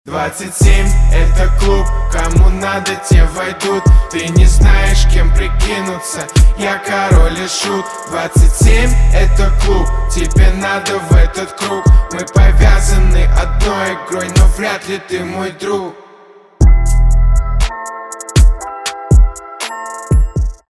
русский рэп
качающие